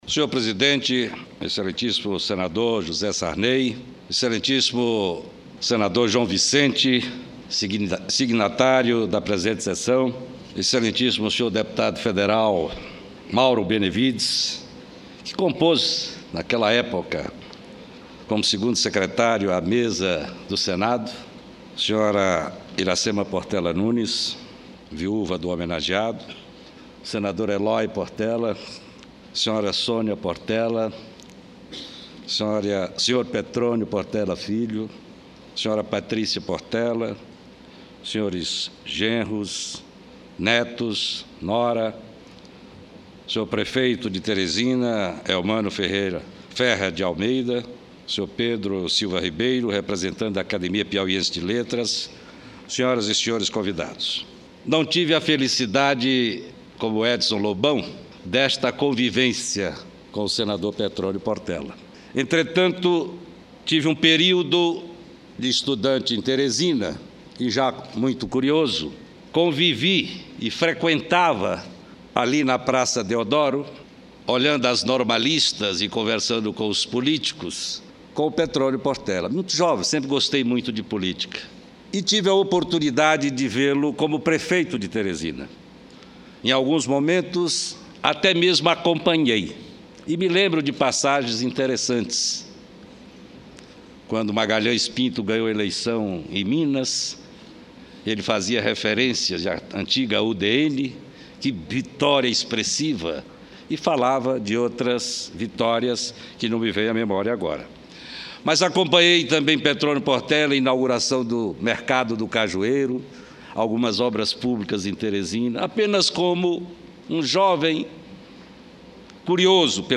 Discurso de homenagem do senador Adelmir Santana